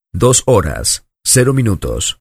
Grabación sonora de locutor retransmitiendo el siguiente comentario: "dos horas, cero minutos"
hombre
locutor
Sonidos: Voz humana